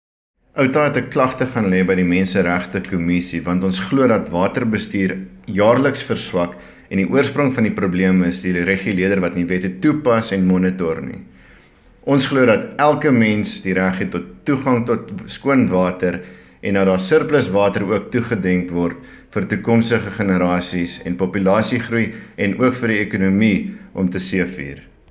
Afrikaans sound bite